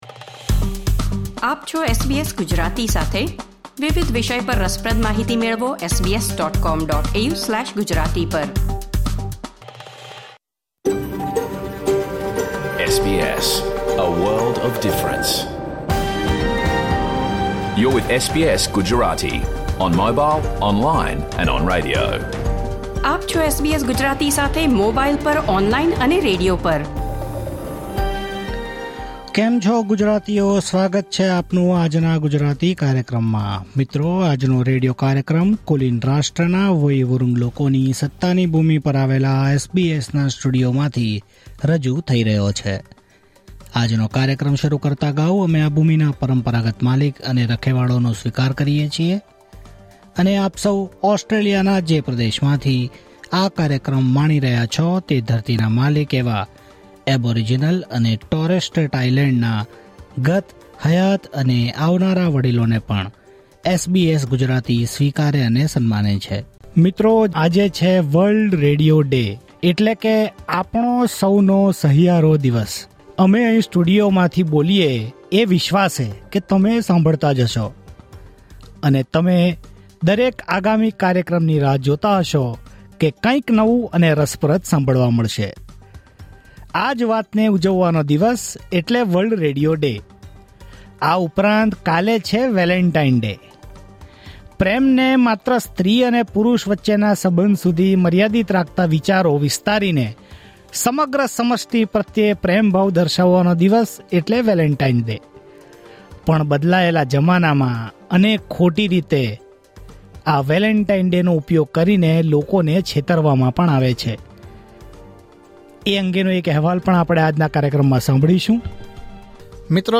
Listen to the full SBS Gujarati radio program